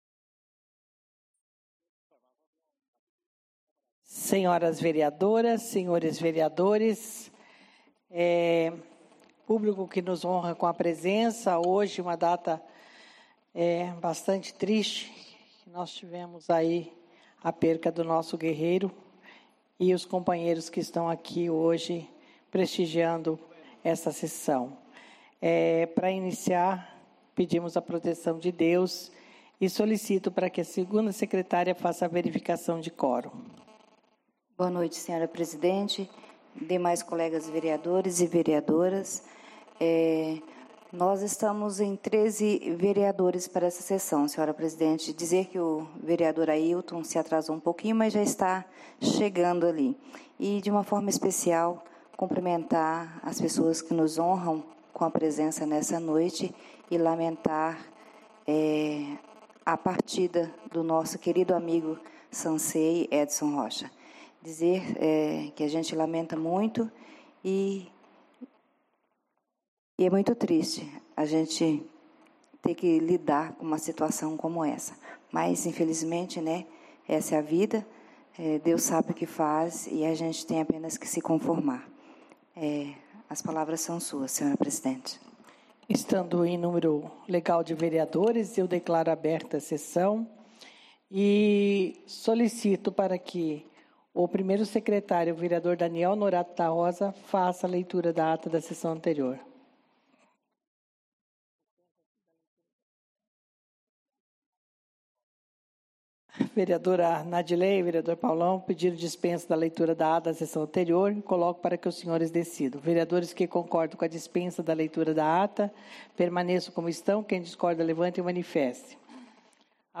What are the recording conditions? Áudio da Sessão Ordinária realizada no dia 19/12/2016 as 19:30 horas no Plenário Henrique Simionatto.